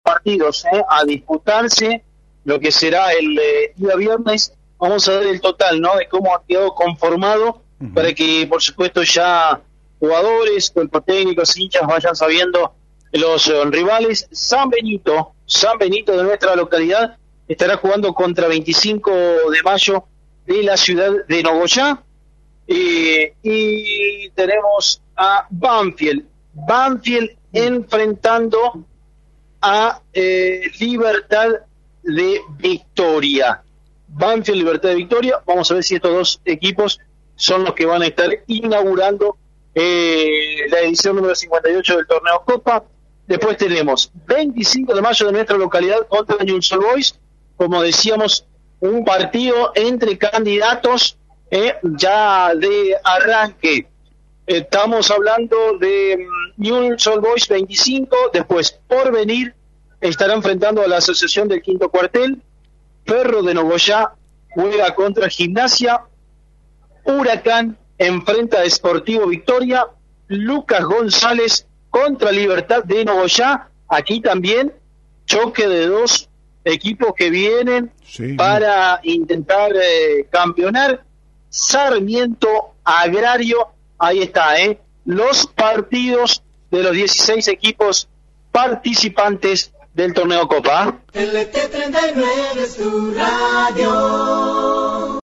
El periodista